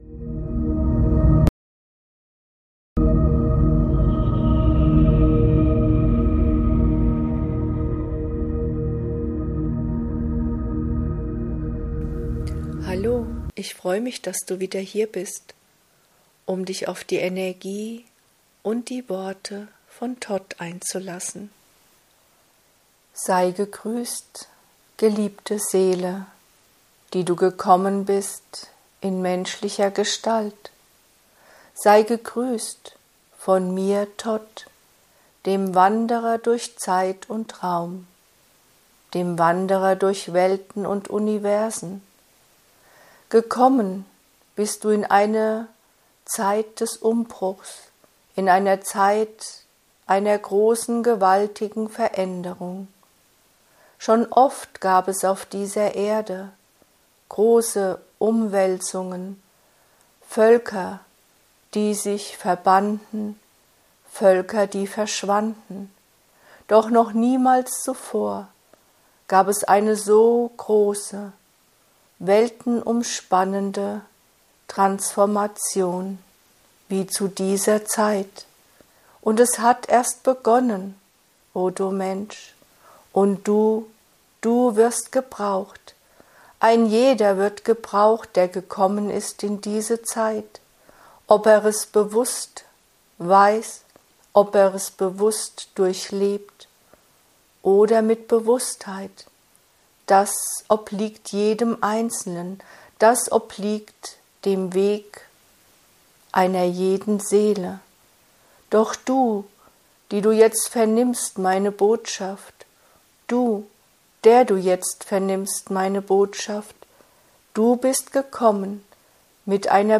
Botschaften aus den hohen Lichtebenen: Dein himmlischer Podcast für die Verbindung zur geistigen Welt. In meinem Podcast findest du durch mich direkt gechannelte Lichtbotschaften.